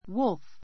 wolf wúlf ウ るふ 名詞 複 wolves wúlvz ウ るヴズ 動物 オオカミ a pack of wolves a pack of wolves オオカミの群れ I am as hungry as a wolf.